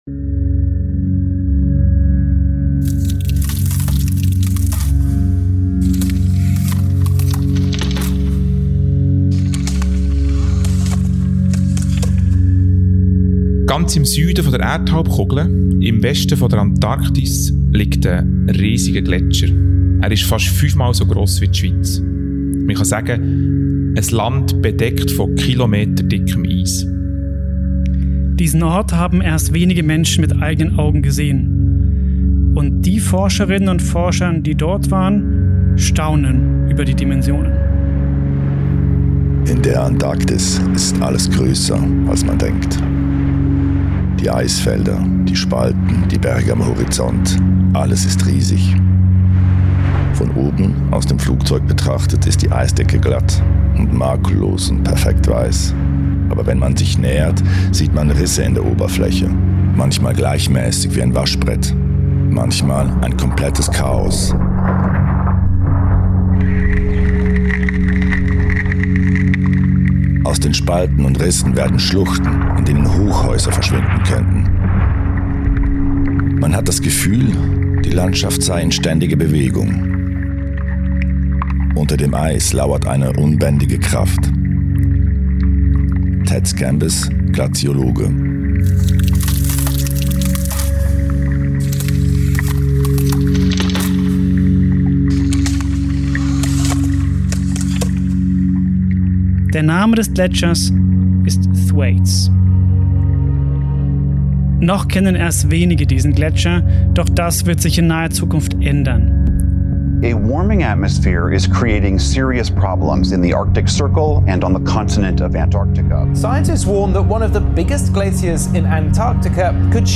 Auf einer Wanderung im Wallis erzählt er, warum die Menschen sich schon immer vor Gletschern fürchteten – und warum diese Angst noch nie so berechtigt war wie heute.